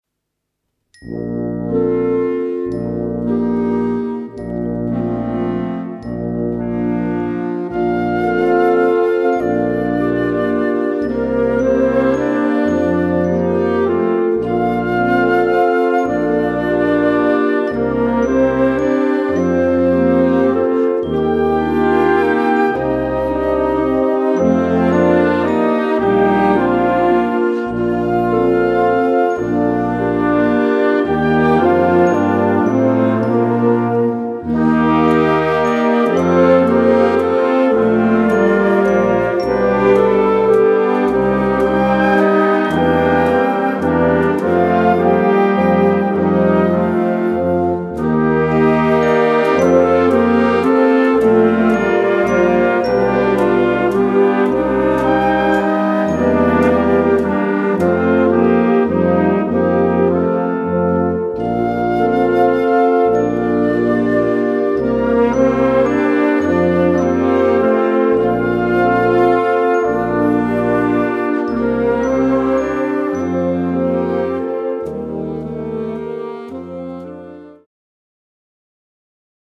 Gattung: Walzer
A4 Besetzung: Blasorchester Zu hören auf